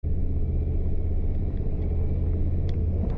На этой странице представлены звуки работы РСЗО \
Звук движения УРАЛ-4320, запись изнутри кабины (Боевая машина БМ-21 системы залпового огня М-21)